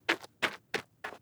Walking.wav